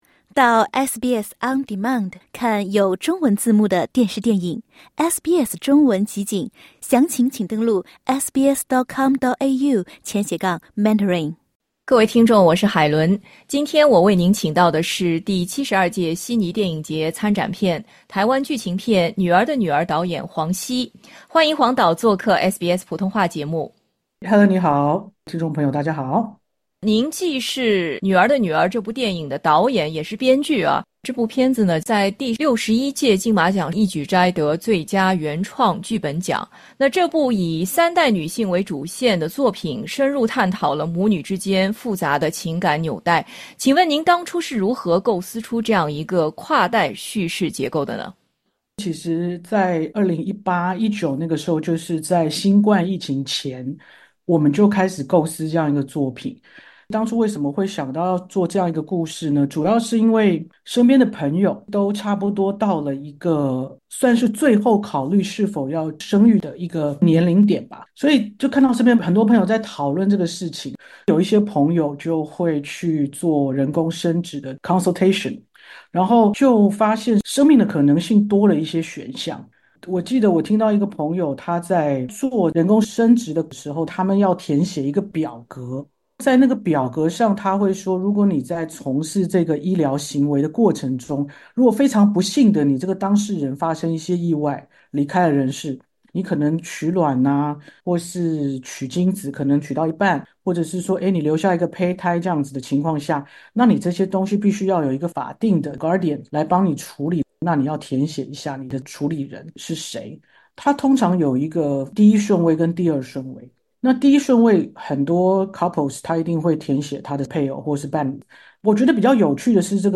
关于这部作品更多精彩的创作心路与深层寓意，请点击以上图标，收听播客专访，一同聆听导演对女性议题的细腻解读与创作背后的温暖初衷。